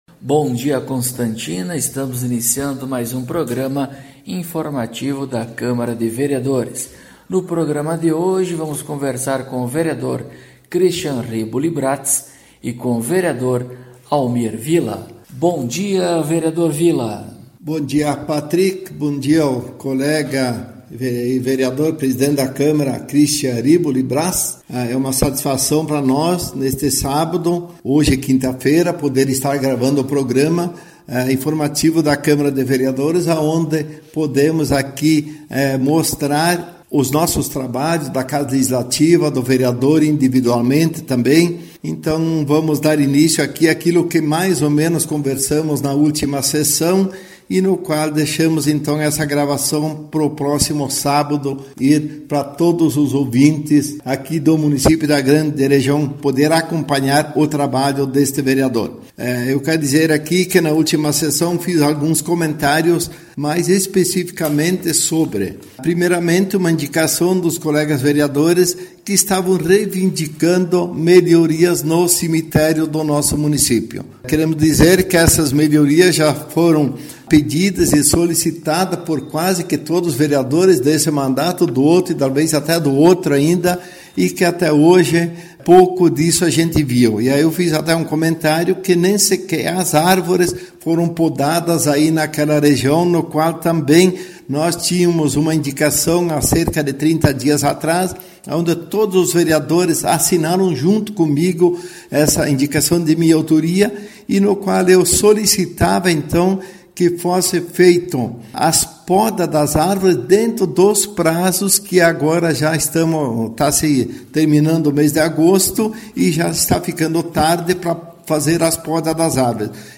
Acompanhe o programa informativo da câmara de vereadores de Constantina com o Vereador Cristian Bratz e o Vereador Almir Villa.